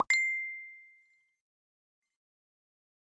messenger_tone.mp3